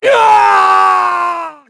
Clause-Vox-Story-Pain_2_kr.wav